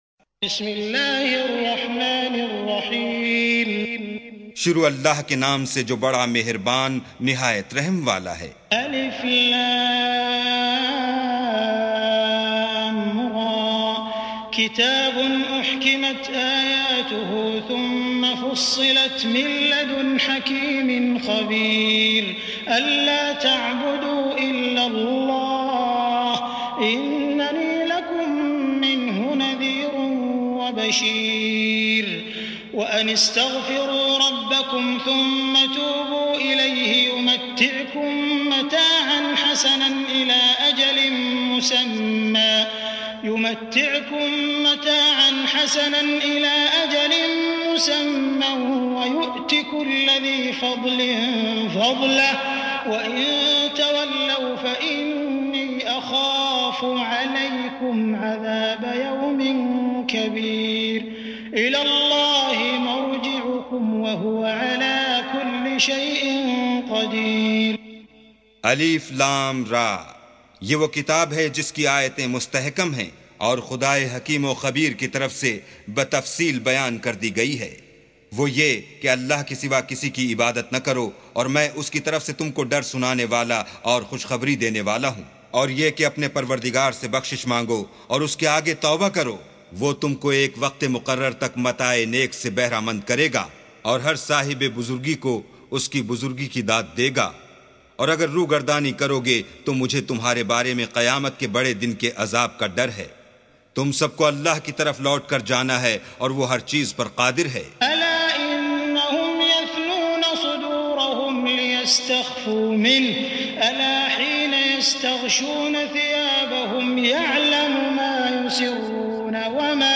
سُورَةُ هُودٍ بصوت الشيخ السديس والشريم مترجم إلى الاردو